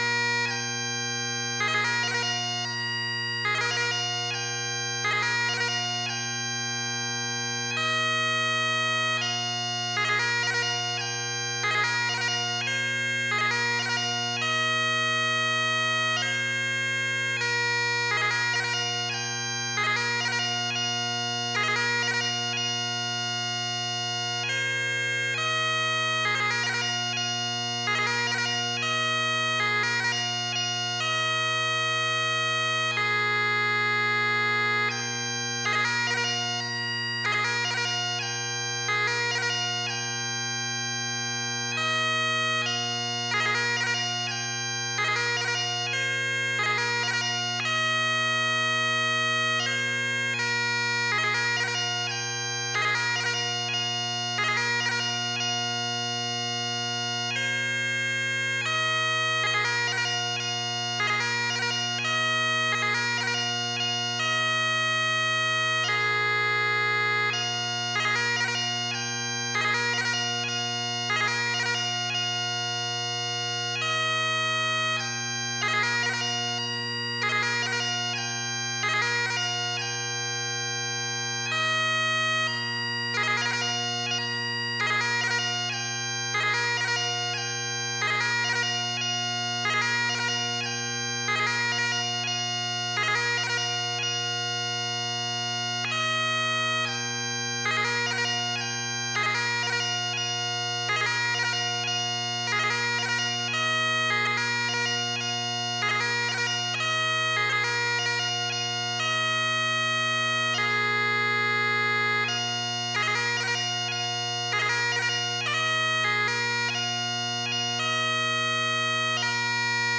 Lament for the Children に関して、いくつかの練習演奏を録音しました。それぞれ、Singling は練習スピード、Doubling は演奏スピード。